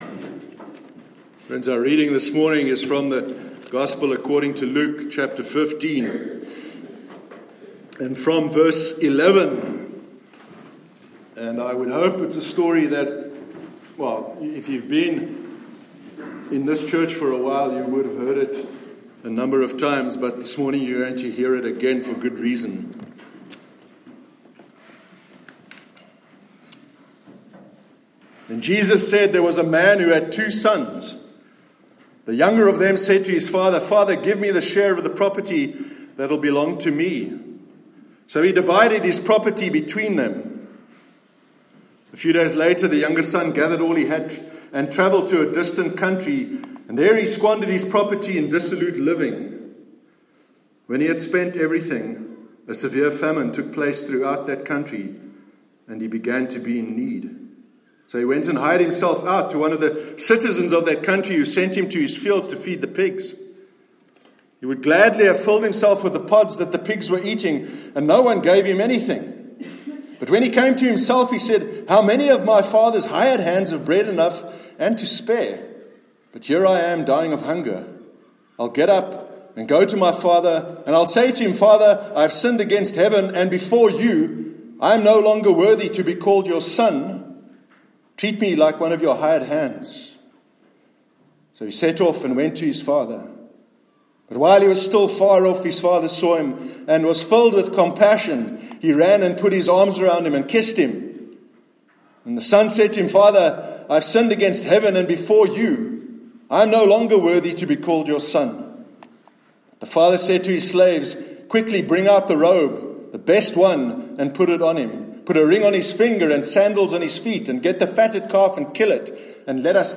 Grace and Kindness- Sermon 23rd June 2019